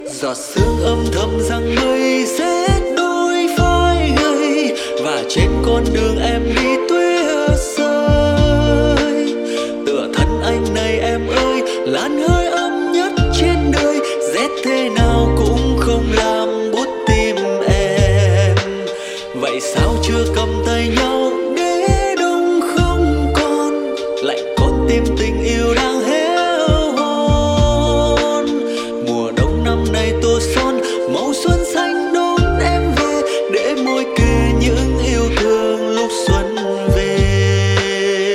Nhạc Lofi.